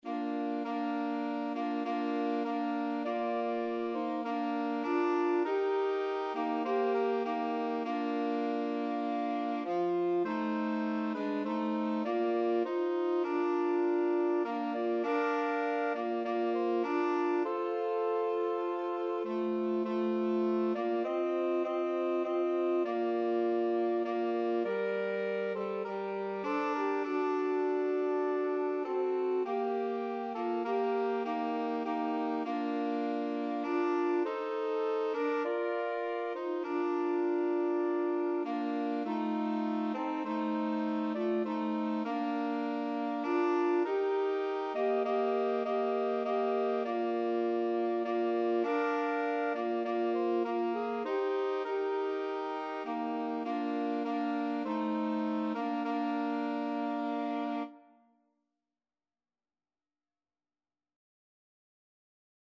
Soprano SaxophoneAlto SaxophoneTenor Saxophone
Woodwind Trio  (View more Easy Woodwind Trio Music)
Classical (View more Classical Woodwind Trio Music)